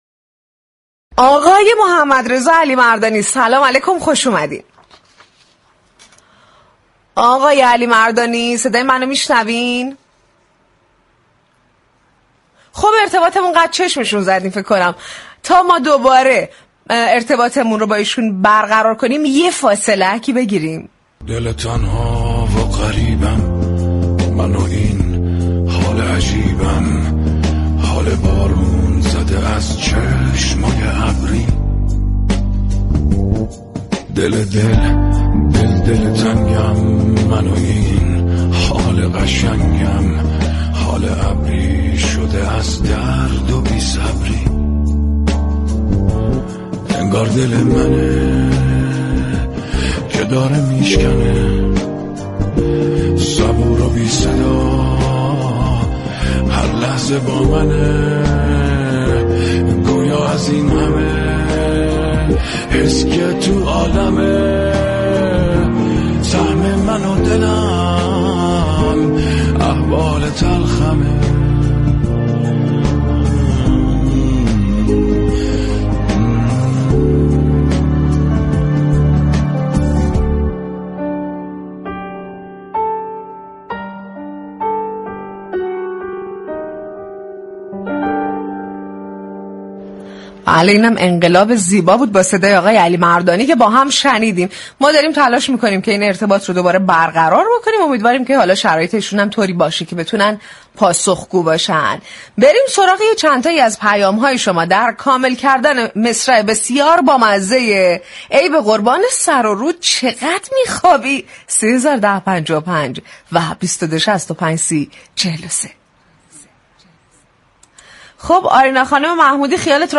محمدضا علیمردانی درگفتگو با «صباهنگ» رادیو صبا درباره حضورش در عرصه های مختلف هنری كم حاشیه بودنش توضیح داد